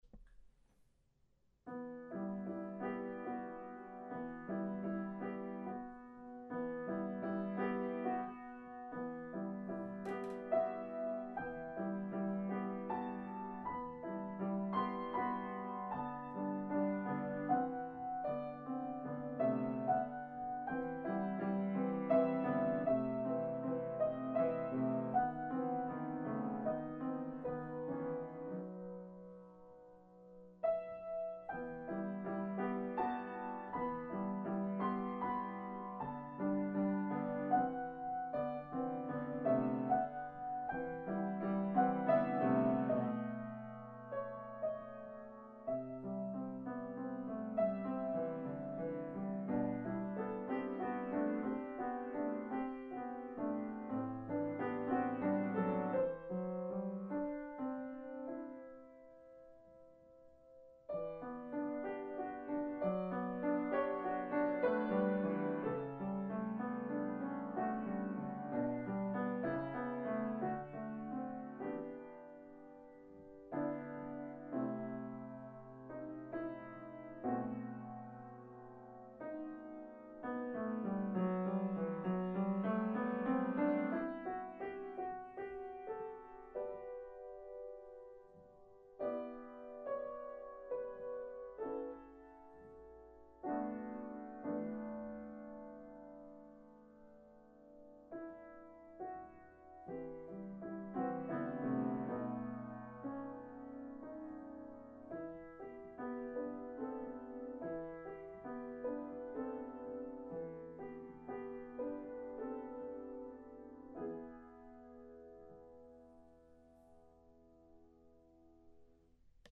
I share this haunting melody with you as a reminder of the sadness we see in our world.